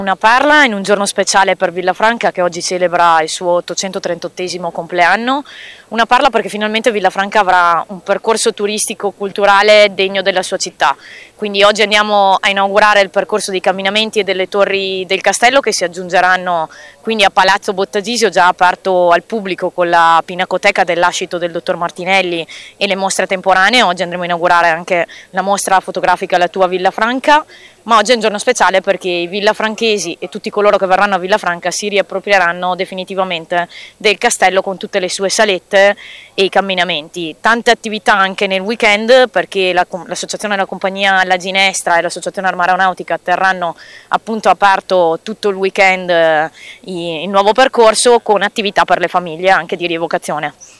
ha intervistato anche Claudia Barbera, Assessore alla Cultura e alle Pari Opportunità
Claudia-Barbera-assessore-alla-cultura-e-alle-pari-opportunita-del-comune-di-Villafranca.mp3